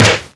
• Natural Snare Sound A Key 66.wav
Royality free acoustic snare tuned to the A note. Loudest frequency: 2070Hz
natural-snare-sound-a-key-66-3ZJ.wav